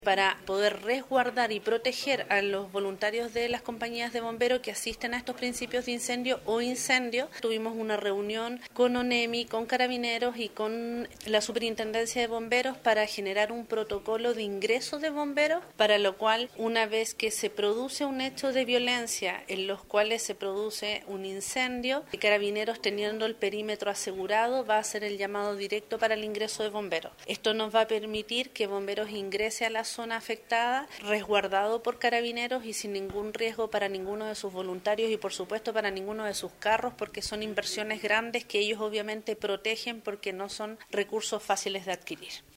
En ese sentido, las autoridades pertinentes se reunieron para abordar medidas de acción, que puedan dar y garantizar la seguridad al trabajo de los voluntarios de bomberos al momento que sean requeridos por alguna emergencia que son provocadas por grupos vandálicos, los detalles fueron dados a conocer por la gobernadora provincial de Llanquihue, Leticia Oyarce.